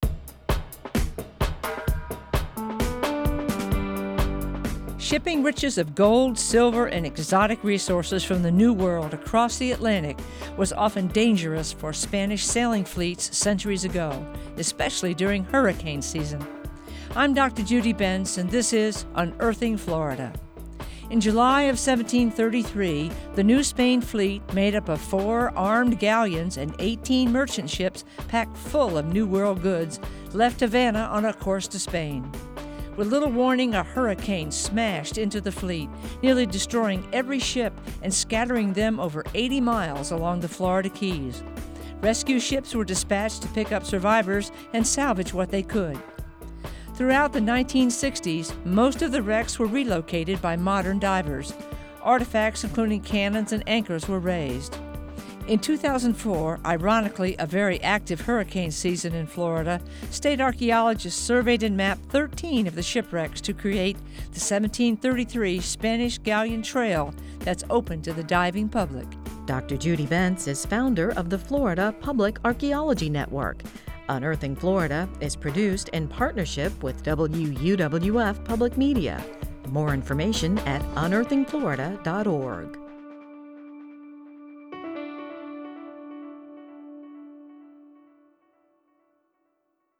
Written, narrated, and produced by the University of West Florida, the Florida Public Archaeology Network, and WUWF Public Media.